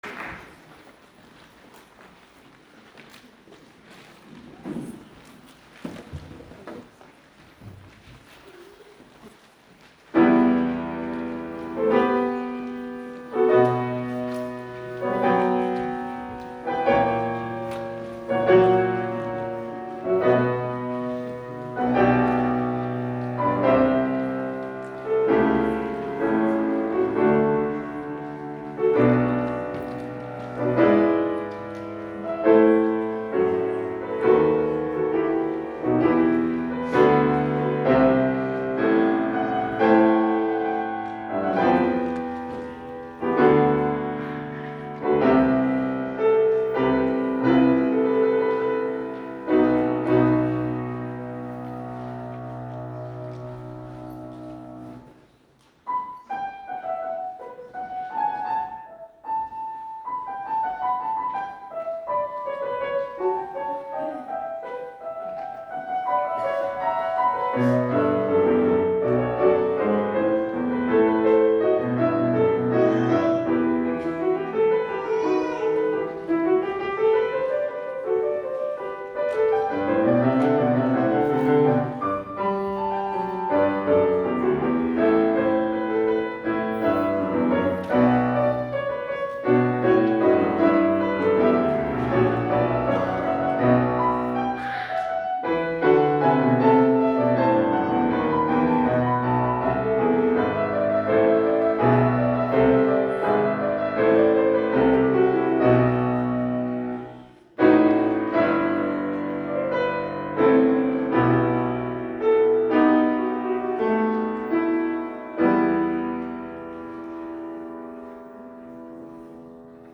This page contains an amateur recording of our Winter Concert held on December 2, 2023 in the St. Michael’s Recital Hall, St. Michael’s College, Colchester, VT.
beautiful piano work
soprano
tenor
alto
bass